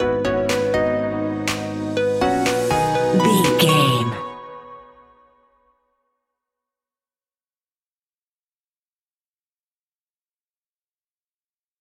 Ionian/Major
groovy
uplifting
energetic
bouncy
synthesiser
drums
strings
electric piano
electronic
synth leads
synth bass